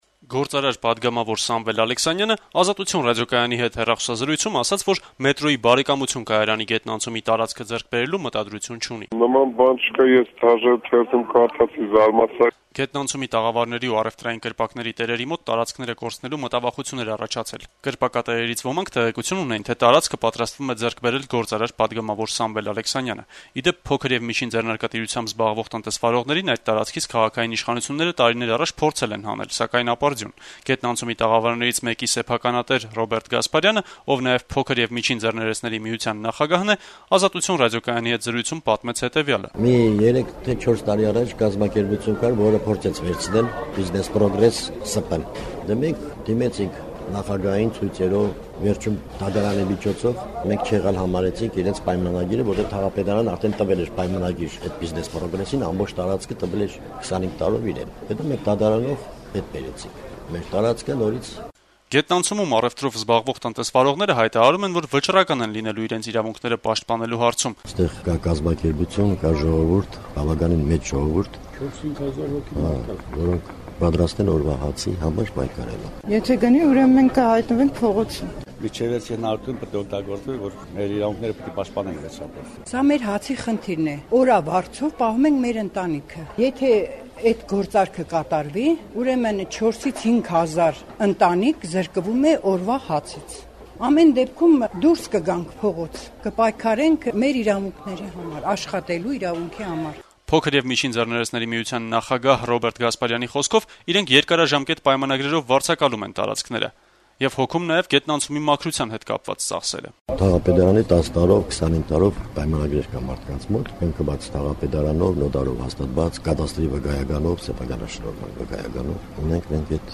Գործարար-պատգամավոր Սամվել Ալեքսանյանը երկուշաբթի օրը, «Ազատություն» ռադիոկայանի հետ զրույցում հերքելով տարածված լուրերը` ասաց, որ մետրոյի «Բարեկամություն» կայարանի գետնանցումի տարածքը ձեռք բերելու մտադրություն չունի: